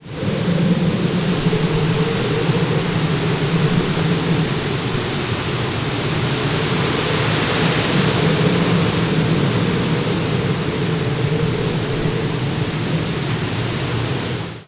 meteo_weather03.wav